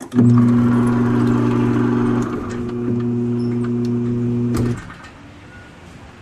Electric Door Close